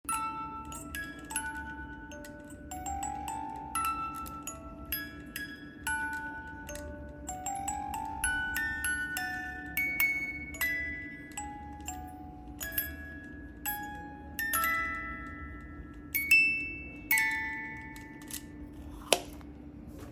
Cajita musical de manivela